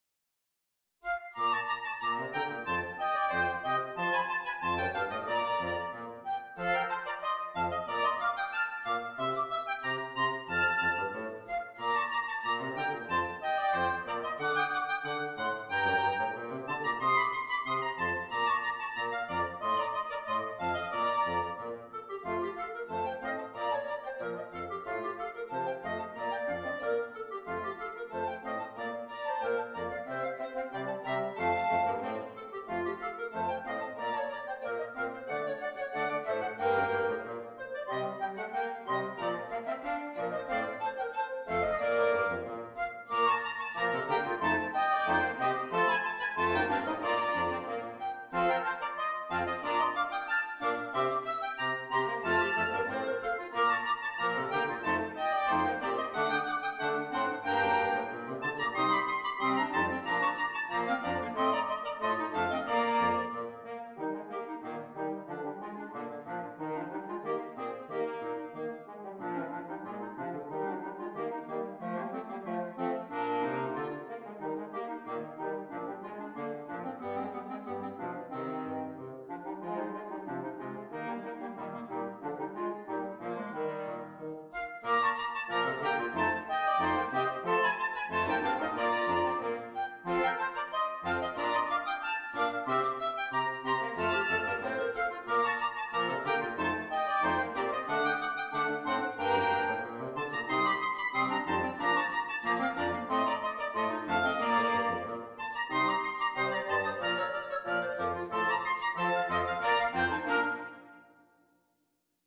Woodwind Quintet